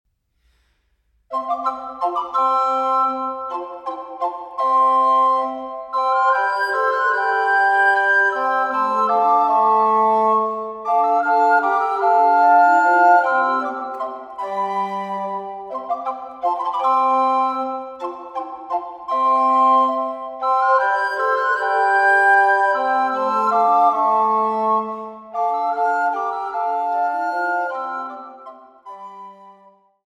Fünfstimmiges Blockflötenconsort